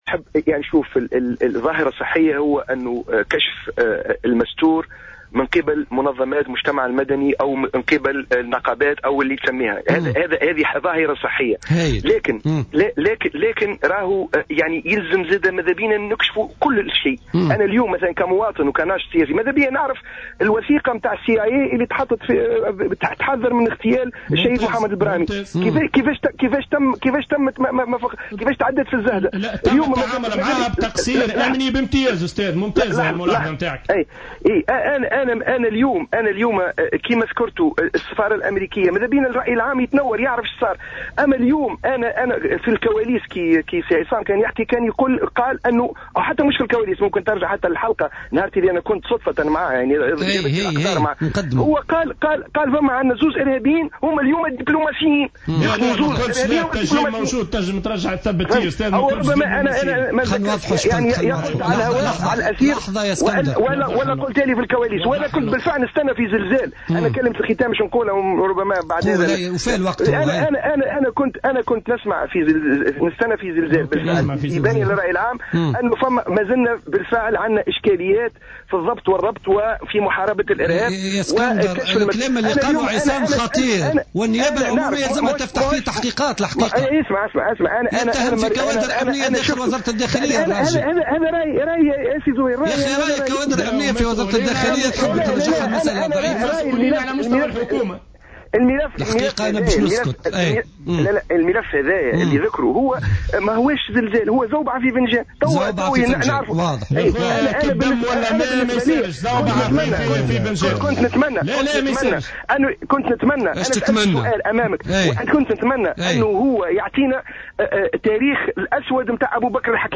علّق السياسي اسكندر الرقيق في مداخلة له اليوم في برنامج بوليتيكا على تصريحات...